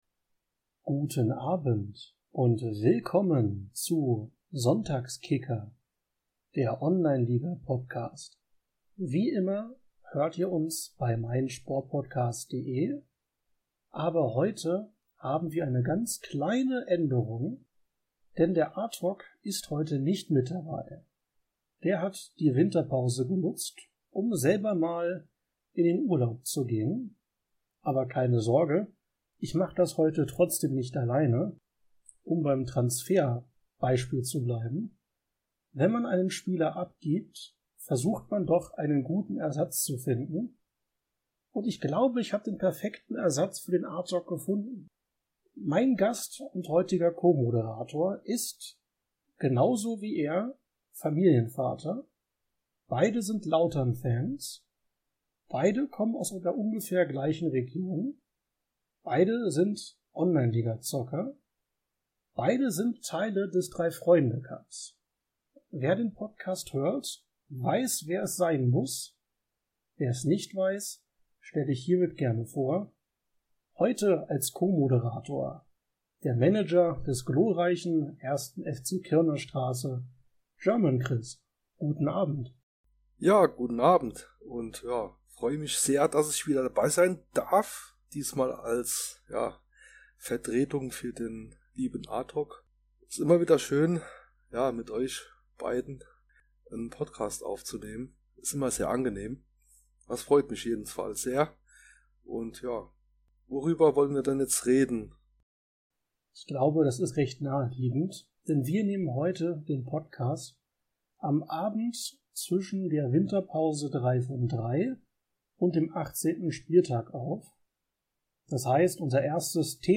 hört ihr keinen Monolog, sondern einen Dialog zwischen den beiden! Sie bleiben bei den Grundsätzen des Podcasts und besprechen das gewesene, die Gegenwart und die Zukunft!